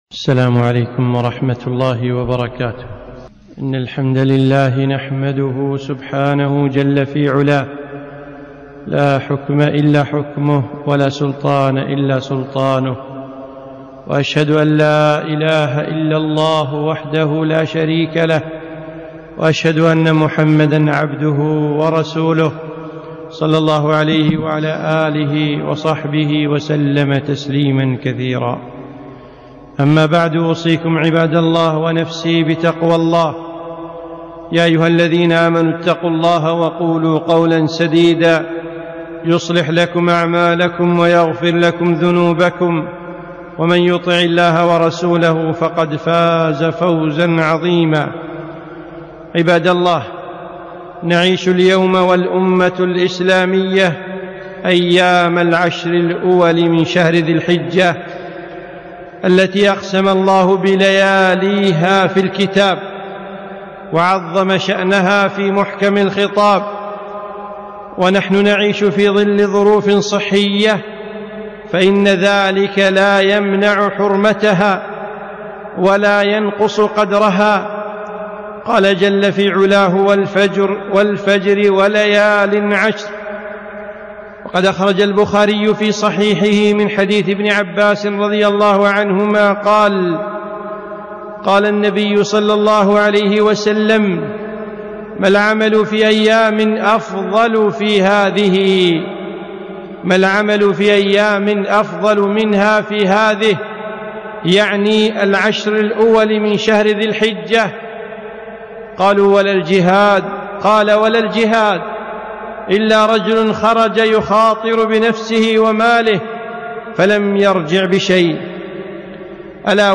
خطبة - اغتنموا العشر من ذي الحجة